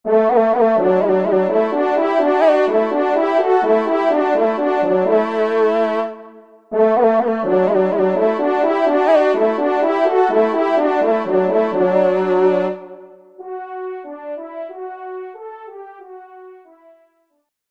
Genre : Fantaisie Liturgique pour quatre trompes
Pupitre 2°Trompe